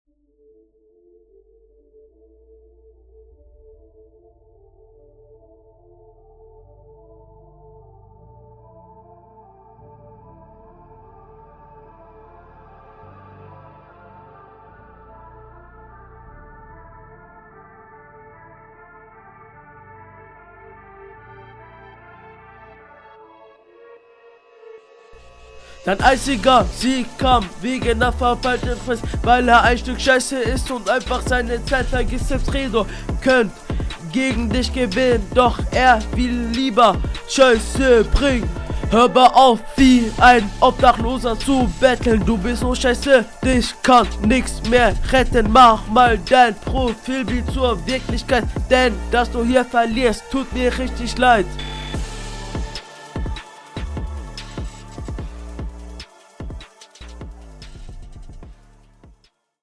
Du klingst ziemlich monoton und dein Flow ist sehr stockend.